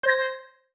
Simple Cute Alert 13.wav